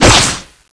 Index of /server/sound/weapons/tfa_cso/dreadnova
slash3.wav